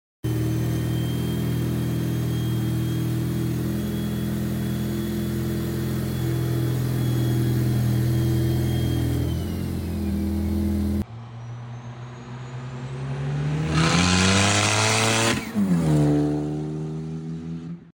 over how rowdy this thing sounds with the t51r mod😏